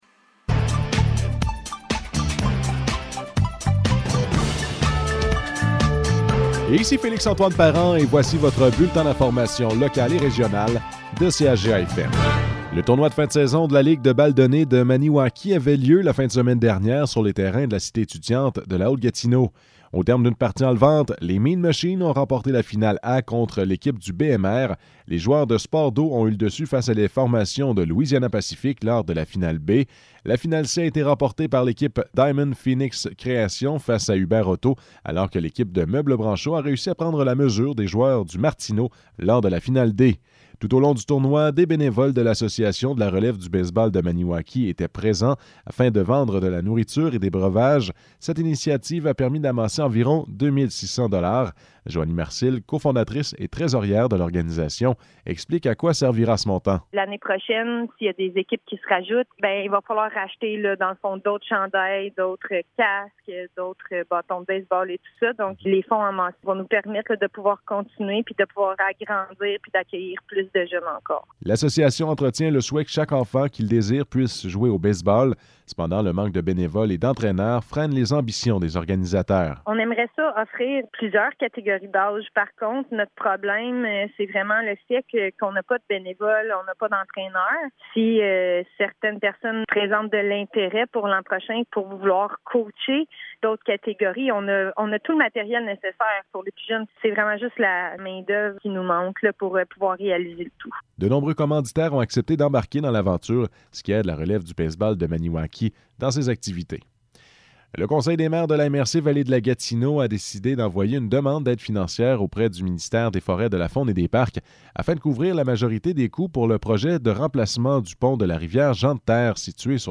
Nouvelles locales - 30 août 2022 - 12 h